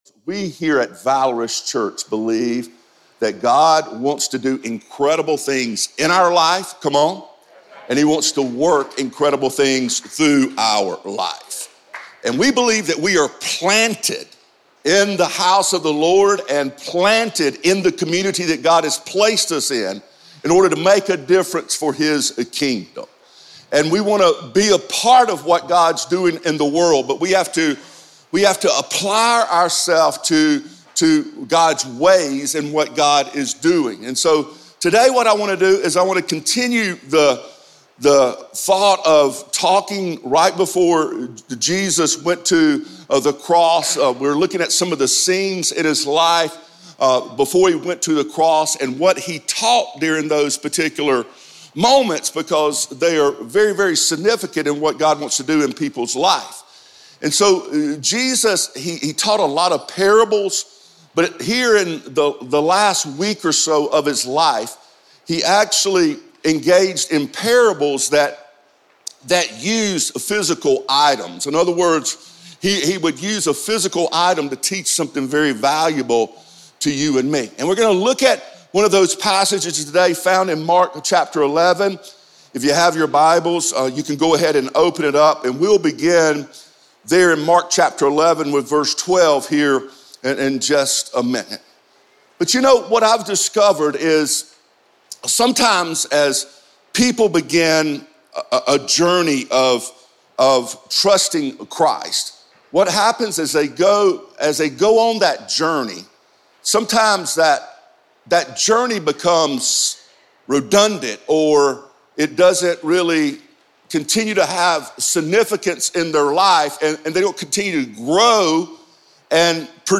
He is known for his ability to captivate and challenge the audience by delivering the Word of God in a way that is relevant to our world today.